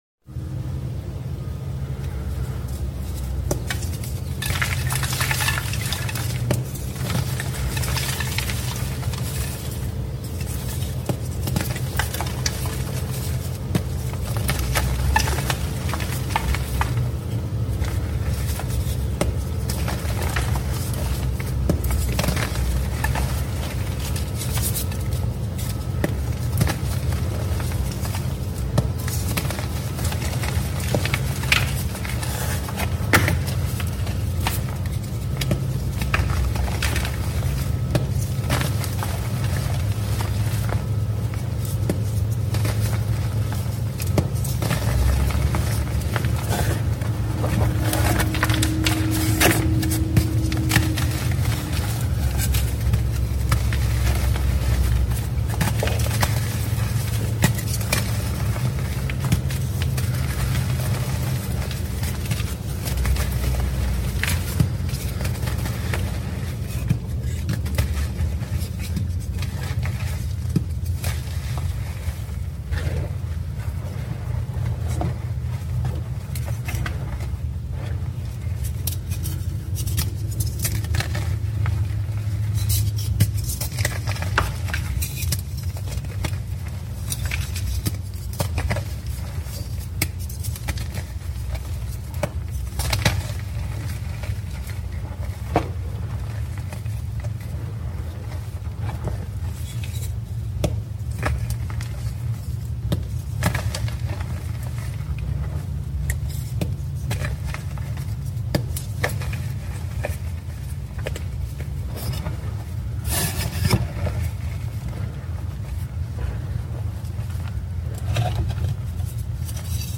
the sound isn't perfect on this one but I still had to post it.